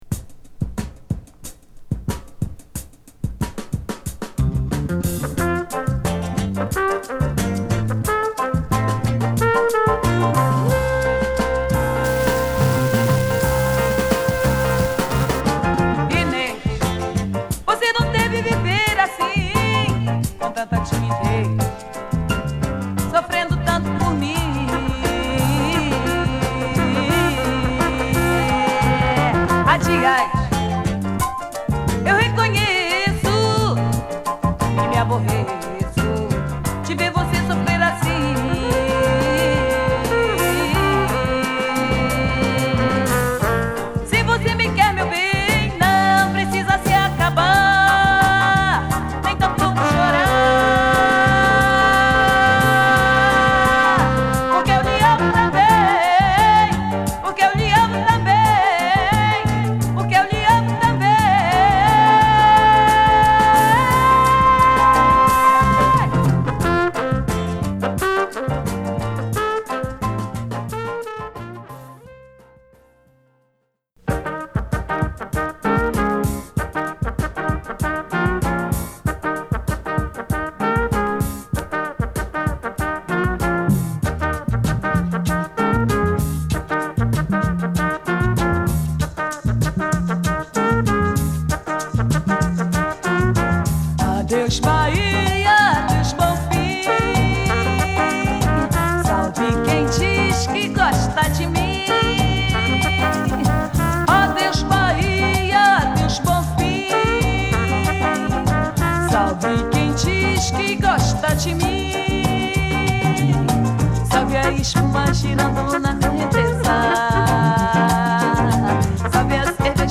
ジャジーな演奏をバックにパワフルな歌声を披露した女性歌手